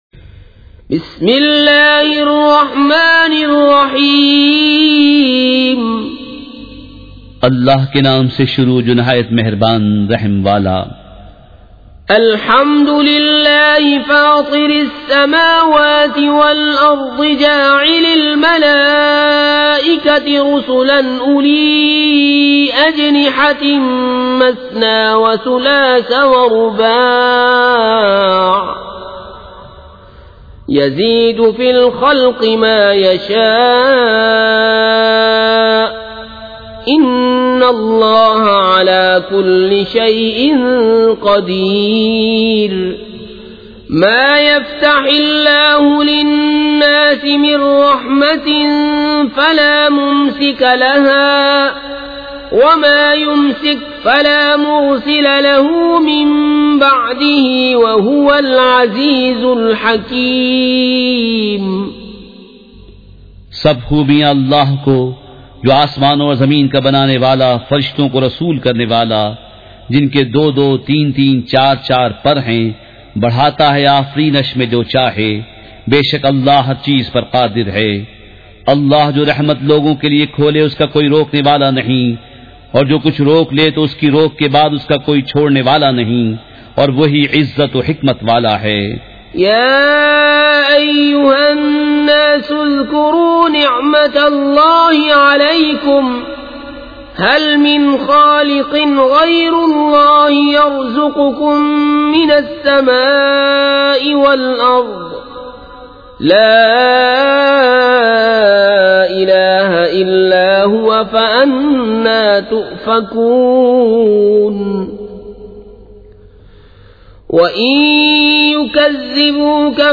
سورۃ الفاطرمع ترجمہ کنزالایمان ZiaeTaiba Audio میڈیا کی معلومات نام سورۃ الفاطرمع ترجمہ کنزالایمان موضوع تلاوت آواز دیگر زبان عربی کل نتائج 1769 قسم آڈیو ڈاؤن لوڈ MP 3 ڈاؤن لوڈ MP 4 متعلقہ تجویزوآراء